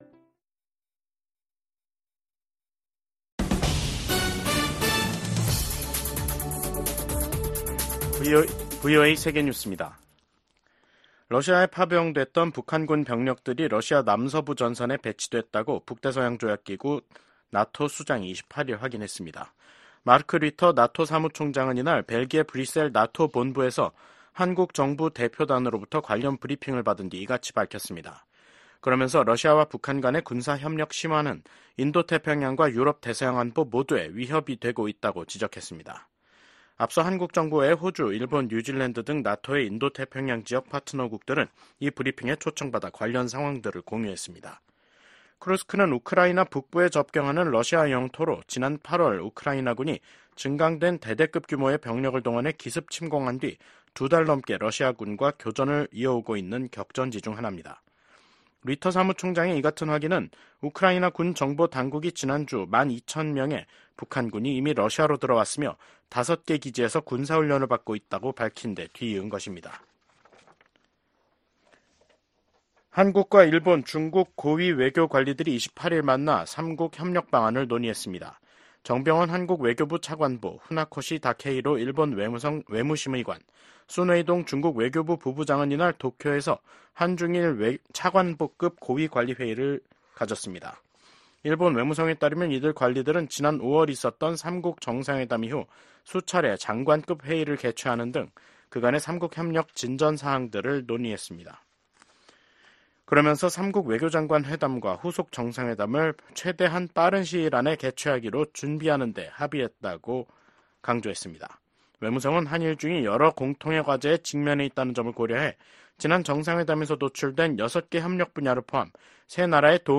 VOA 한국어 간판 뉴스 프로그램 '뉴스 투데이', 2024년 10월 28일 2부 방송입니다. 러시아에 파견된 북한군의 역할에 관심이 집중되고 있는 가운데 미한외교 안보 수장이 워싱턴에서 ‘2+2회담’을 갖고 해당 현안을 논의합니다. 미국과 한국, 일본의 안보 수장들이 워싱턴에서 만나 북한군의 러시아 파병에 깊은 우려를 나타냈습니다.